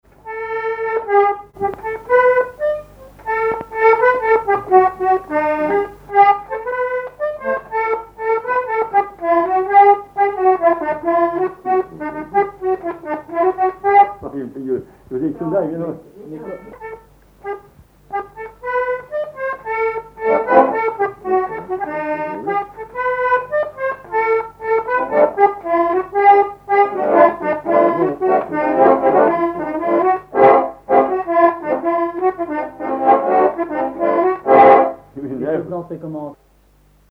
Commequiers ( Plus d'informations sur Wikipedia ) Vendée
danse : polka piquée
accordéon diatonique
Pièce musicale inédite